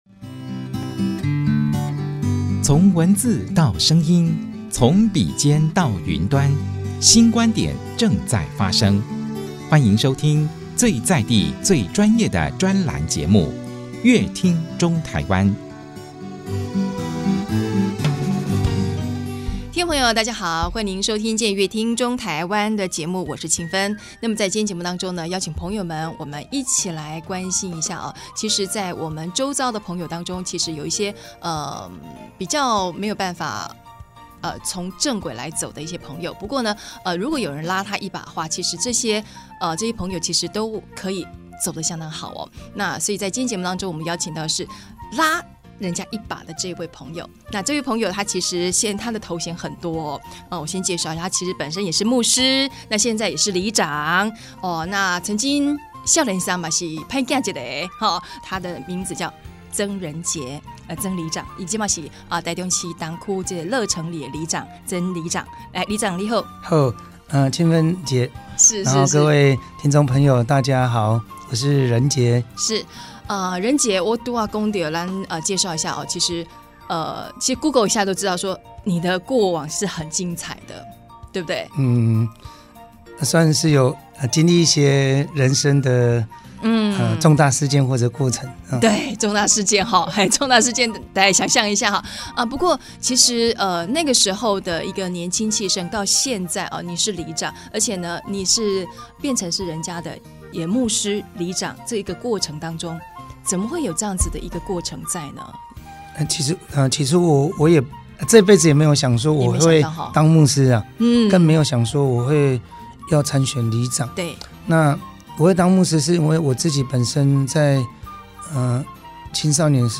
本集來賓：台中市樂成里曾仁杰里長 本集主題：「黑道大哥變人生導師」 本集內容： 曾經混黑道、在賭場抽保護費、吸
所以在今天節目中邀請曾仁杰里長和大家分享他從黑道大哥變成人生導師的生命故事。